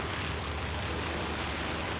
attack_loop.ogg